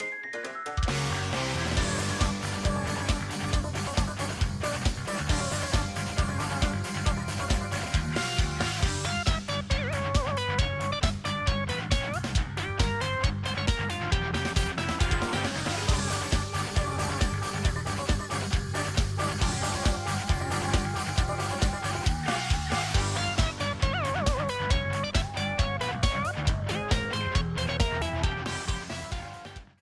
A battle theme
Ripped from the game
clipped to 30 seconds and applied fade-out